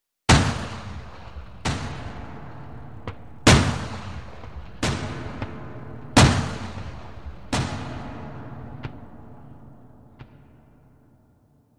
shots-multiple.mp3